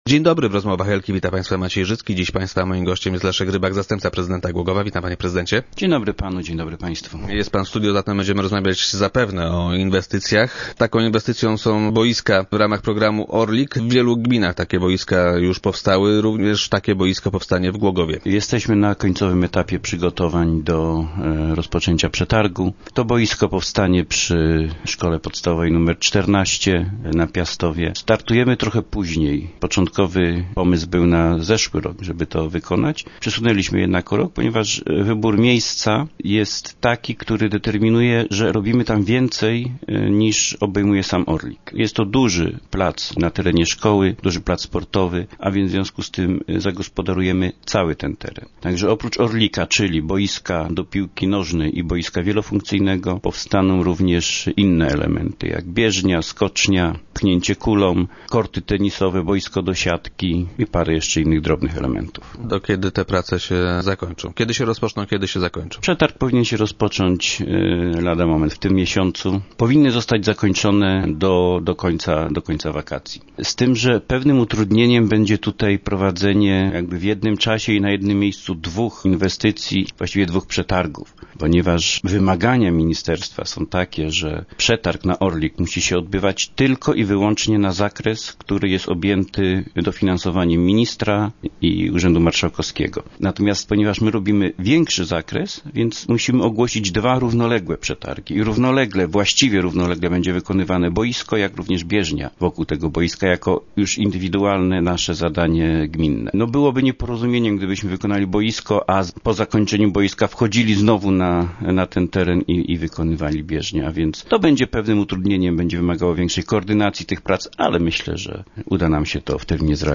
- Będzie to bardzo poważne zadanie - powiedział Leszek Rybak, zastępca prezydenta Głogowa, który był dziś gościem Rozmów Elki.